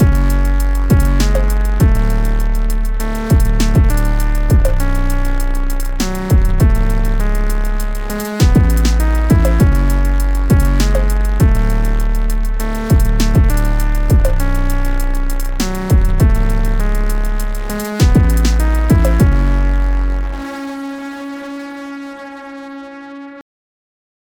Just used a lil trappy beat I had on my M:S, and made sure to carefully align everything so it was in phase.
I didn’t get the hardware plugin part set up so everything is recorded through my audio interface into Reaper from the pedal’s stereo output.
Heavy Parallel:
Love the heavy parallel sound that adds some lovely harmonics.